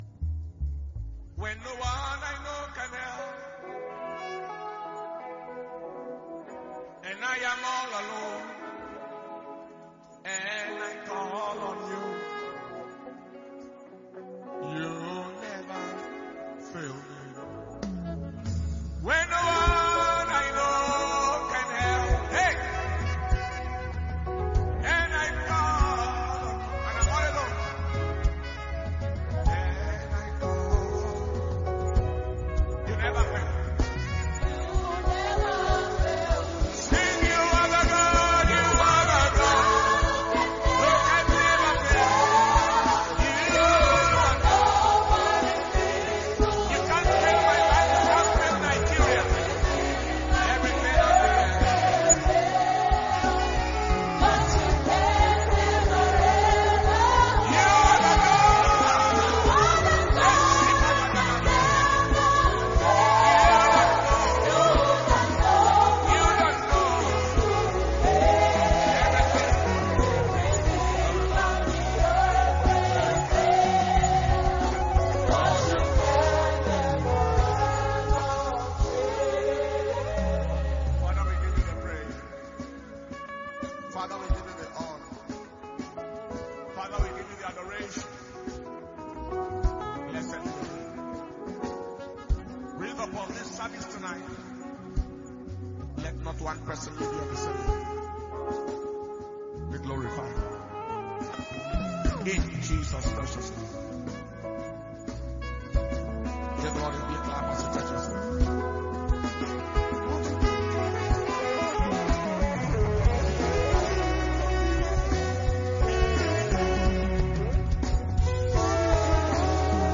June 2023 Preservation And Power Communion Service – Wednesday 7th, June 2023.